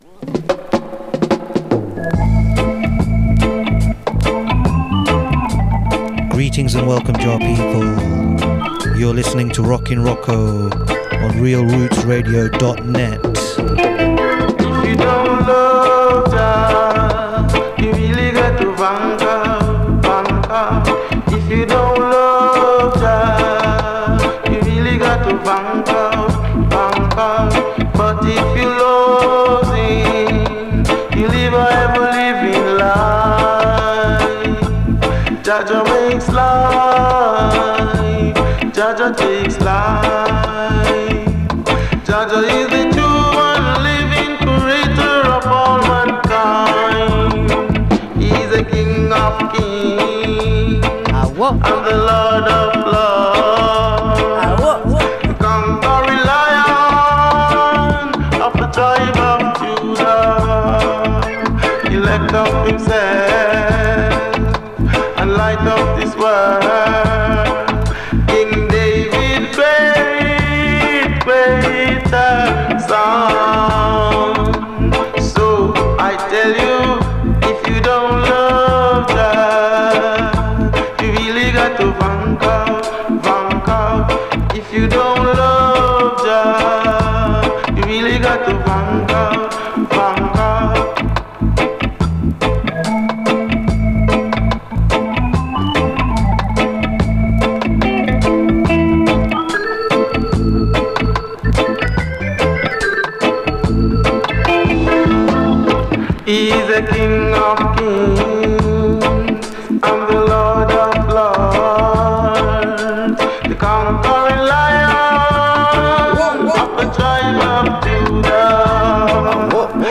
Reggae
Here is the audio of the 1.5hr extended session.